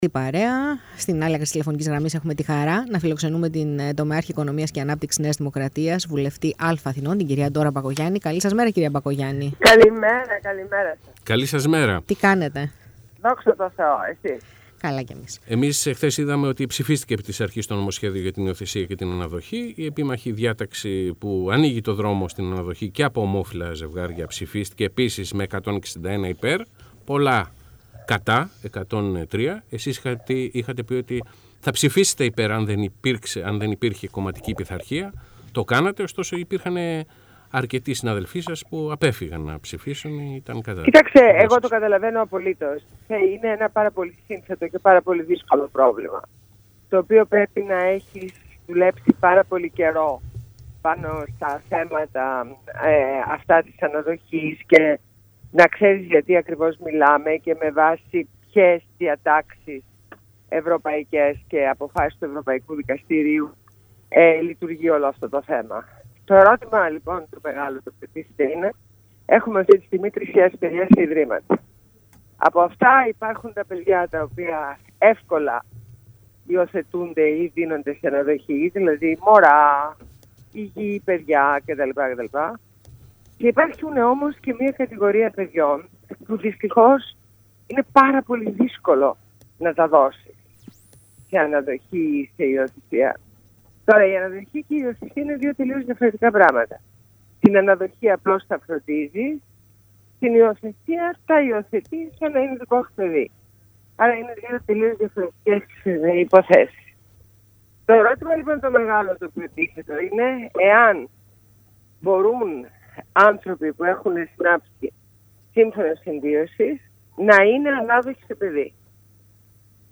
Συνέντευξη στο ραδιόφωνο του ΑΠΕ ΠΡΑΚΤΟΡΕΙΟfm